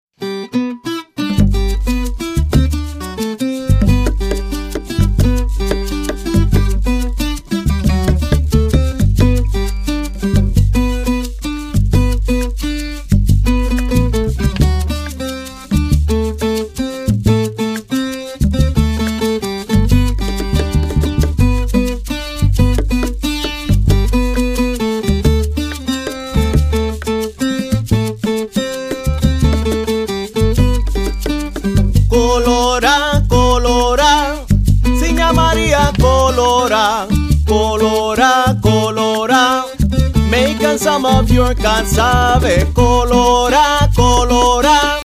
. it’s all there in its multi-instrumental glory.